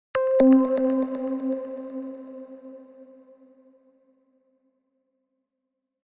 Scifi 3.mp3